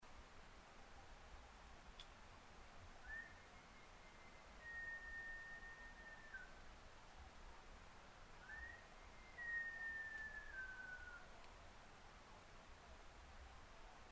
ニホンジカ　Cervus nipponシカ科
日光市稲荷川上流　alt=1330m
Mic: Panasonic WM-61A  Binaural Souce with Dummy Head
かなり遠くから山の空気を通してラッティングコールが聞こえてきます。